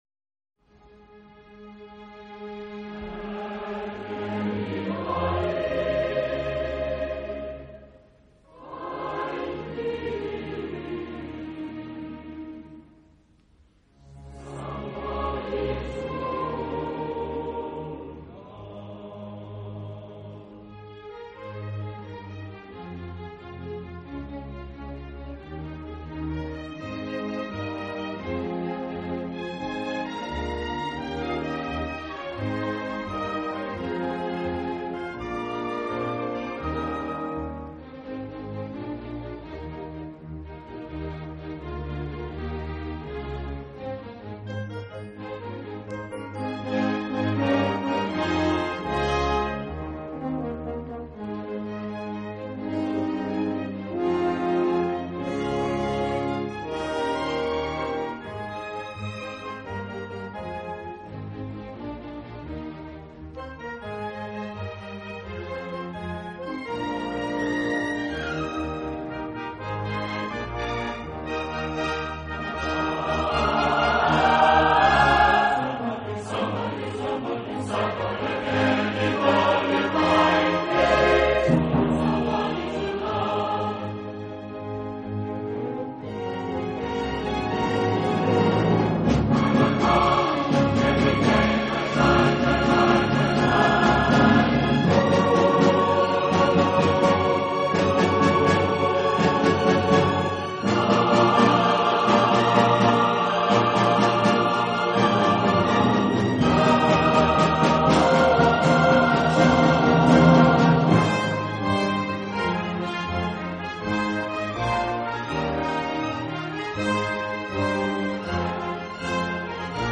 本专辑录音气势磅礴，场域宽阔，器乐丰富，带给您全新的感受。